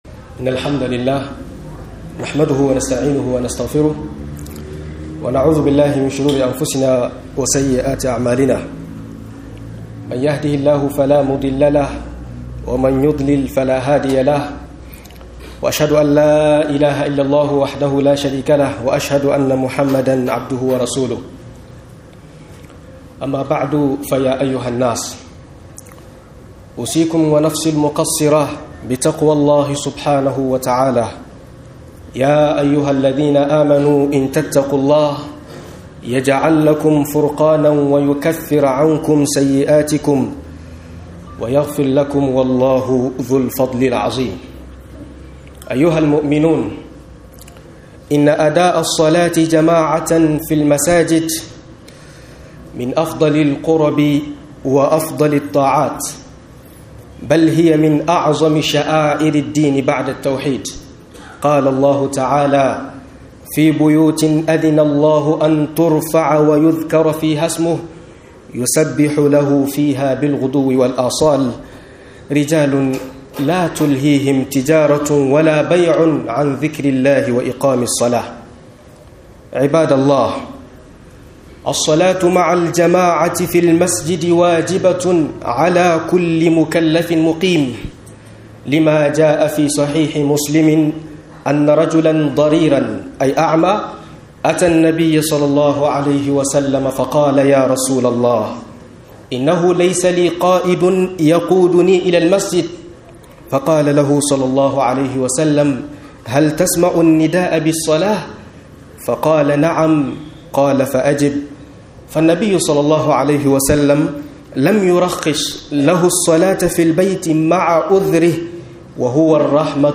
Kwadaytar wa game da sallar jam'i - MUHADARA